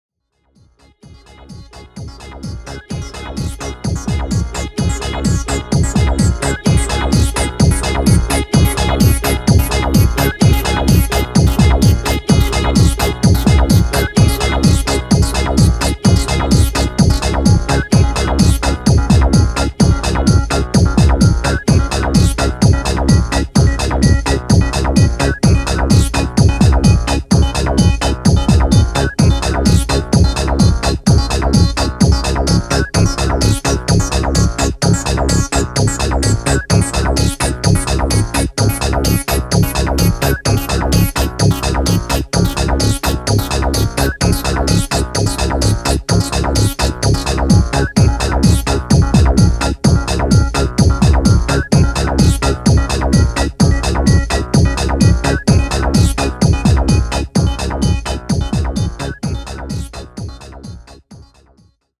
アップリフトな